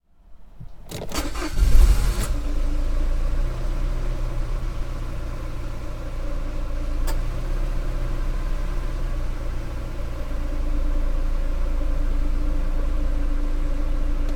engine_start.ogg